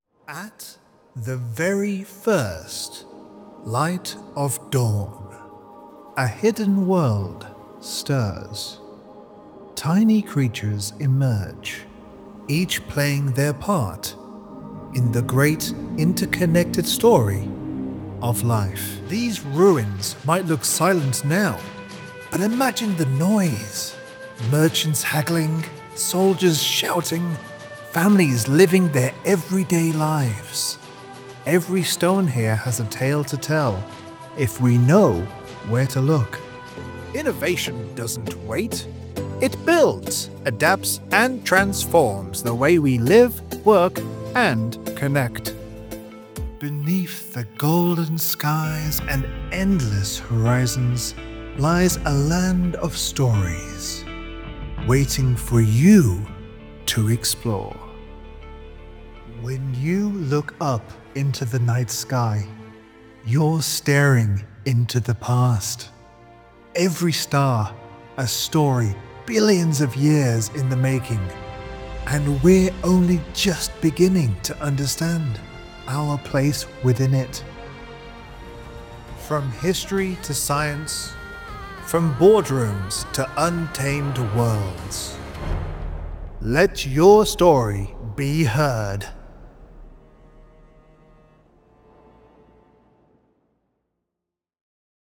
E-learning Voice Over Narration Services | E-book voices
Adult (30-50)